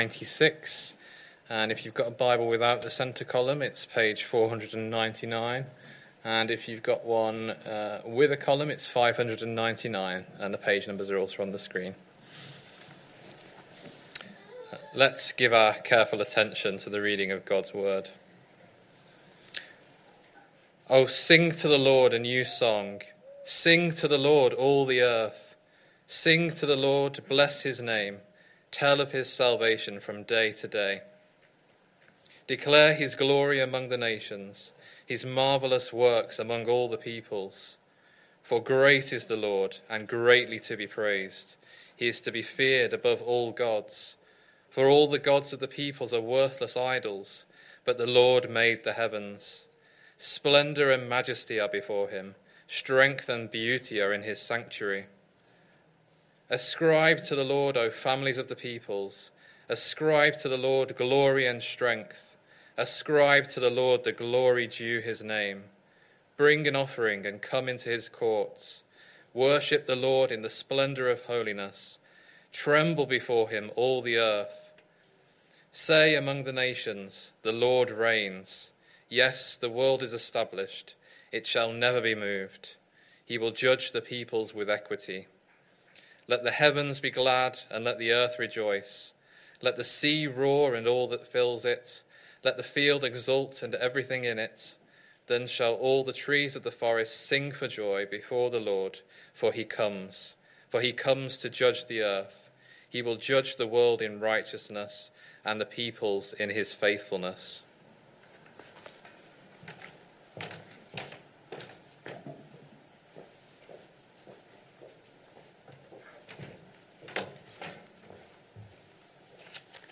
Sunday PM Service Sunday 2nd November 2025 Speaker